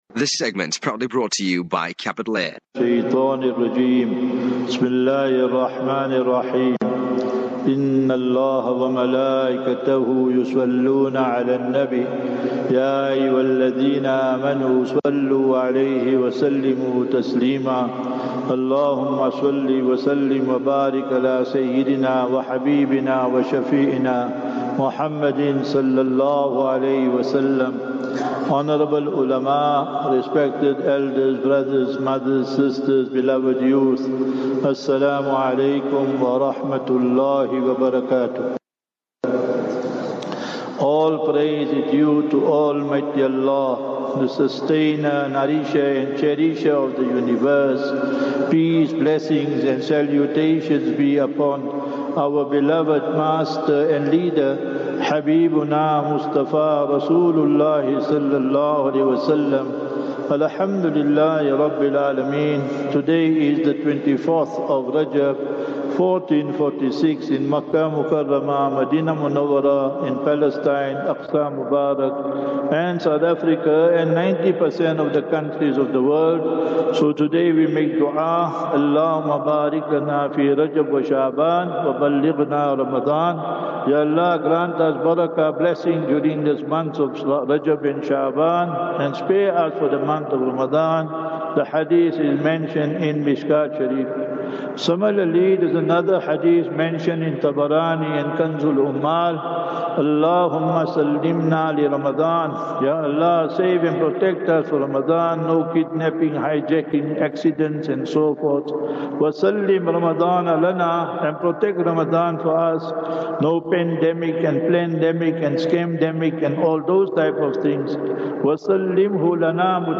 Jumuah Lecture at Masjid Imdaadiya